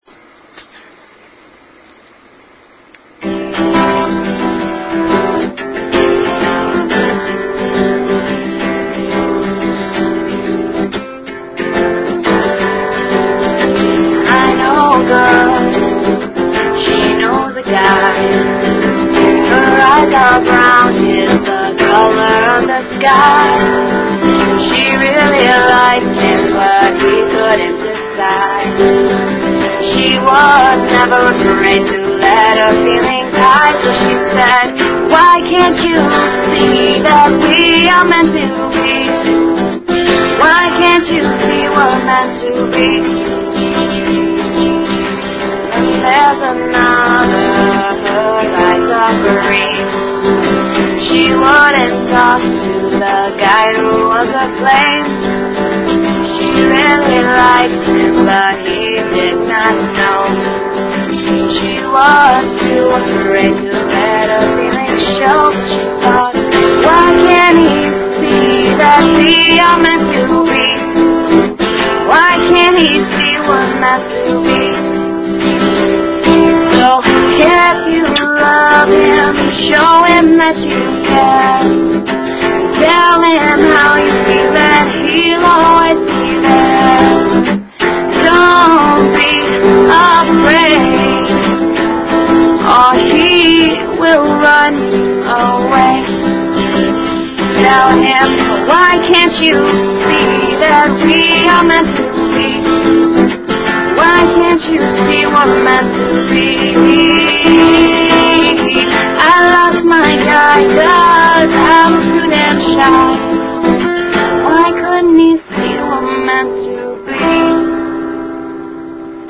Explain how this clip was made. Hopefully updating this sometime soon (got the software and mic, just need to get off my butt and get it going) Once again sorry the sound quality is insanely bad.